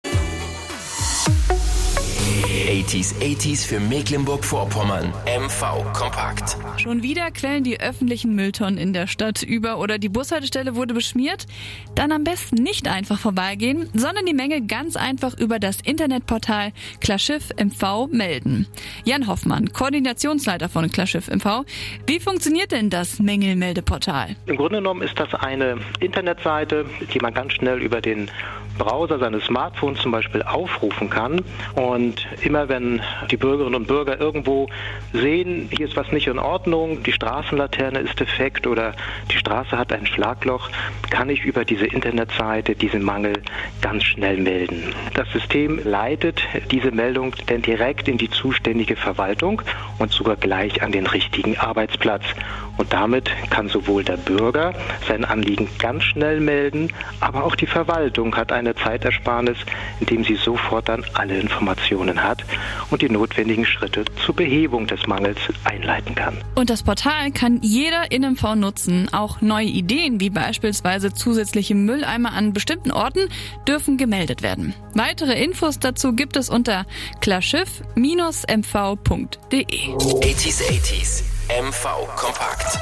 MitschnittKlarschiffMV.mp3